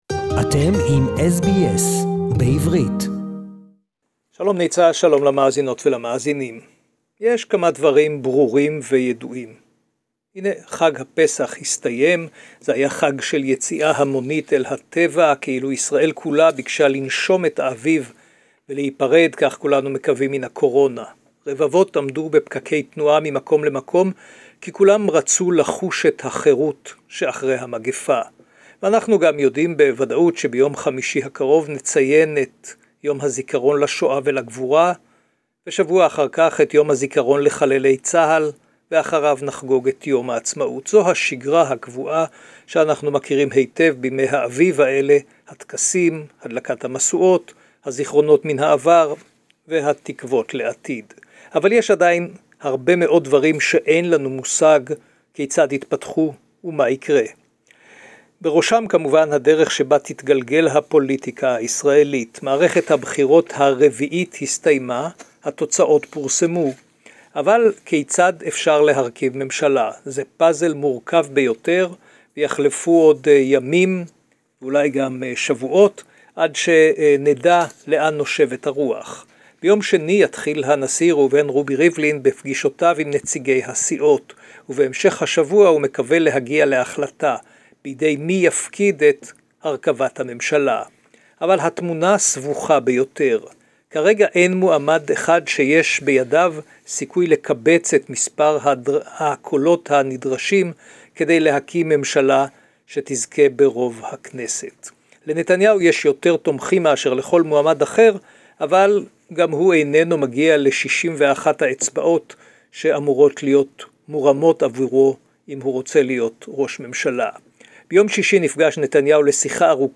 Who will be Israel's next PM? SBS Jerusalem report